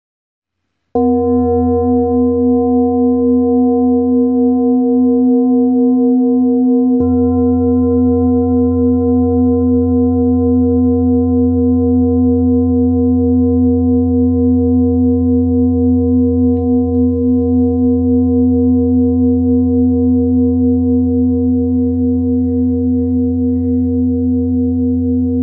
High Quality Peter Hess Singing Bowls, Hand Hammered Clean Finishing L2, Select Accessories , A bowl used for meditation and healing, producing a soothing sound that promotes relaxation and mindfulness
Singing Bowl Ching Lu Kyogaku
Material 7 Metal Bronze
Tibetan bowls emit very pure tones, close to sine waves.
Like a bell, the tone is produced by striking the side of the bowl with a wooden mallet.